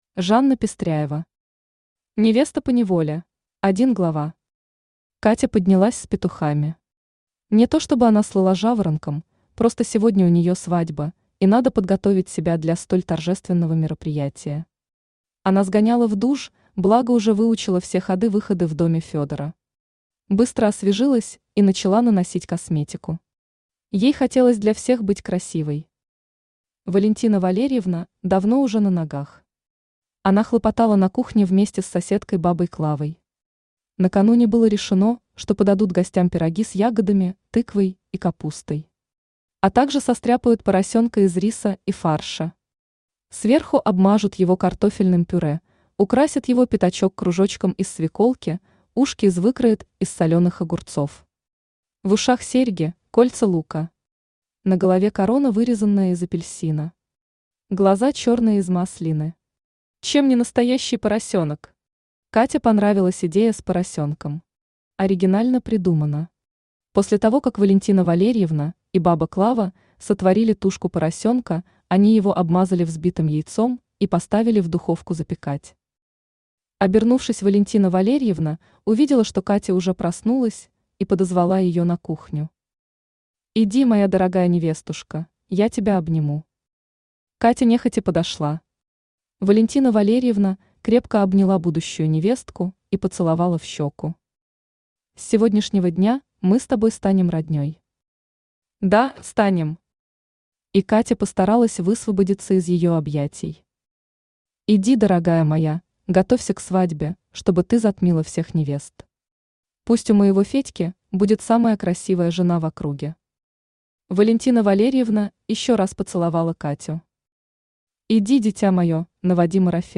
Aудиокнига Невеста поневоле Автор Жанна Пестряева Читает аудиокнигу Авточтец ЛитРес.